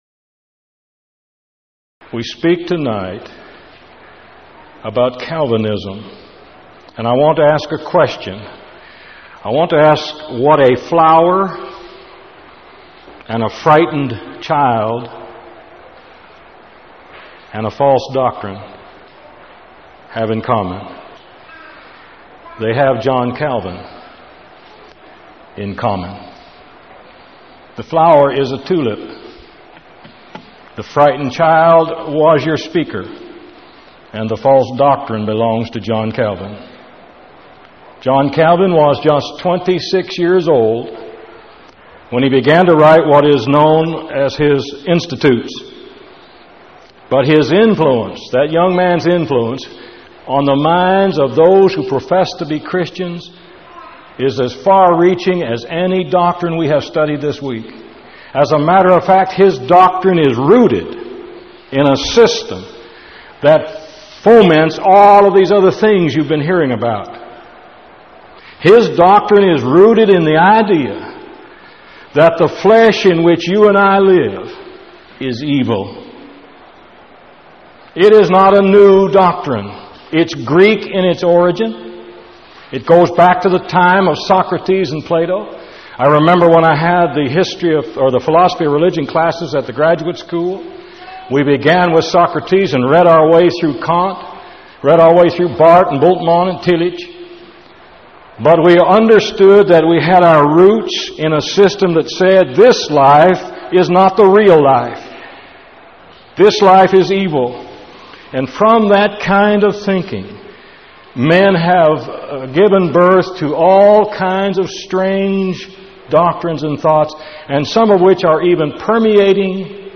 Event: 1997 Power Lectures
lecture